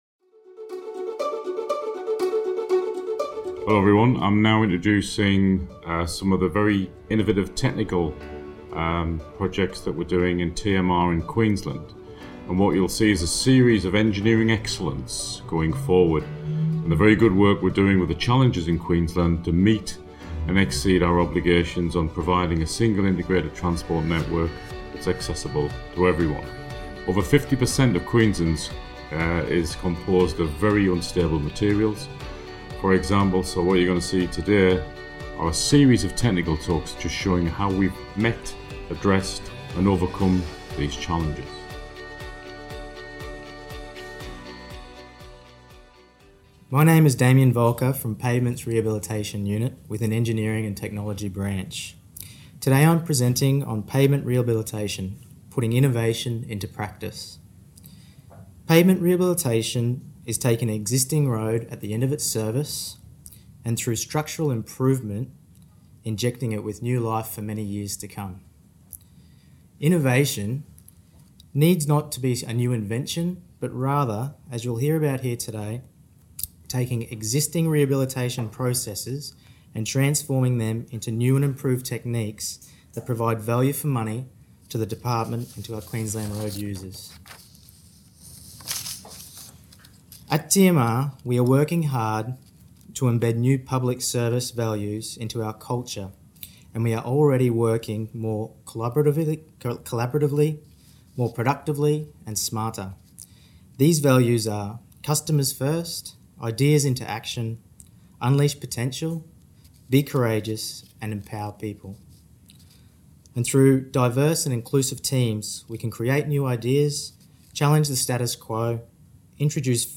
Technical presentations